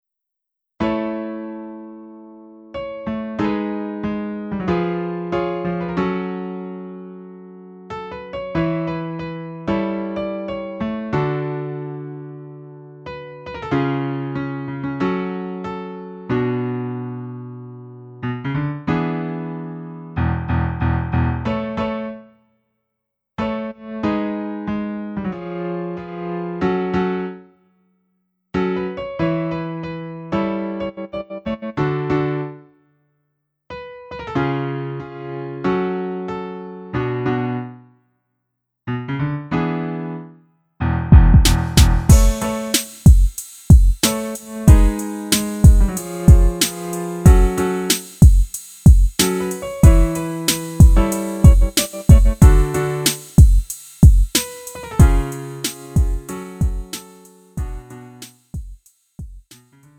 음정 -1키 3:50
장르 가요 구분 Lite MR
Lite MR은 저렴한 가격에 간단한 연습이나 취미용으로 활용할 수 있는 가벼운 반주입니다.